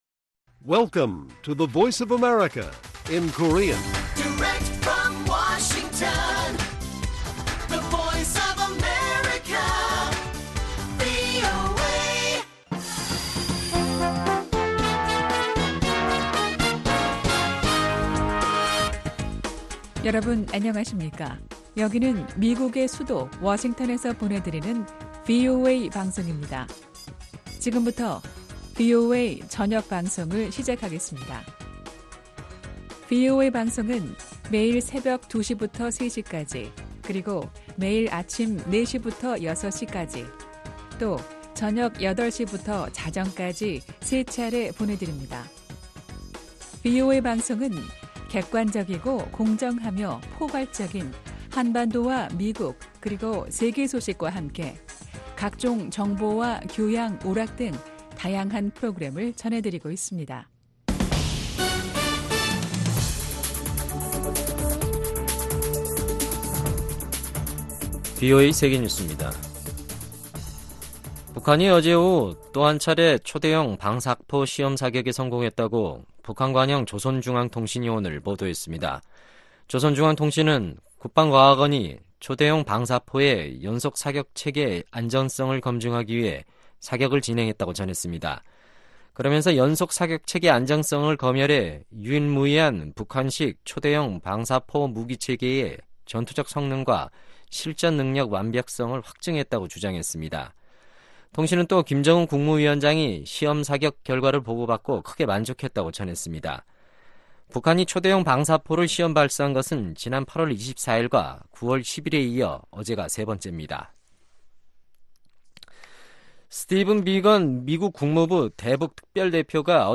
VOA 한국어 간판 뉴스 프로그램 '뉴스 투데이', 2019년 11월 1일 1부 방송입니다. 북한의 도발은 미-북 대화에 악영향을 끼친다고 미 국무부 정치군사 담당 차관보가 말했습니다. 미국의 상원의원들은 북한의 발사는 미국의 관심을 끌기 위한 것이라며, 트럼프 대통령은 이에 단호하게 대응해야 한다고 주장했습니다.